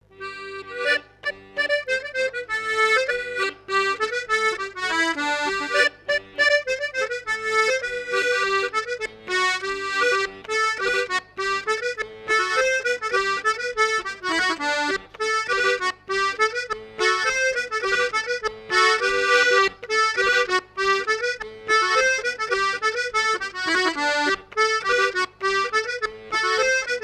danse : scottich trois pas
Fête de l'accordéon
Pièce musicale inédite